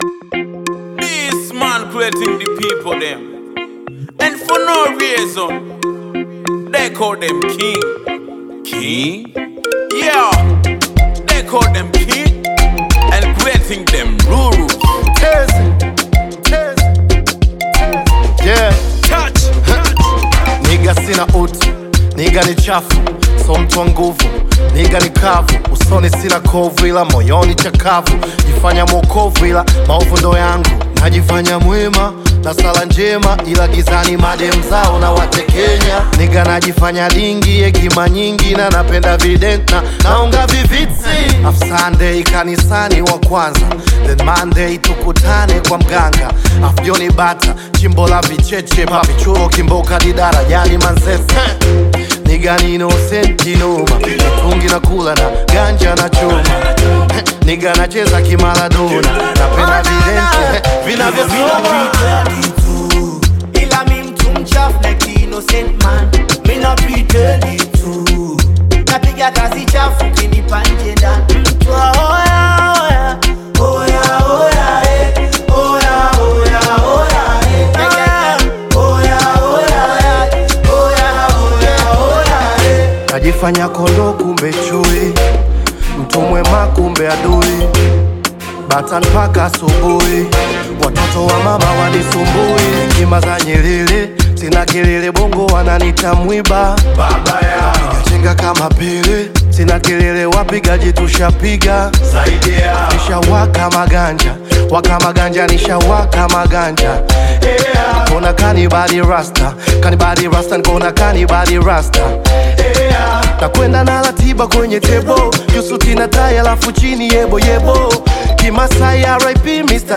Tanzanian Bongo Flava artist, rapper, singer and songwriter
Bongo Flava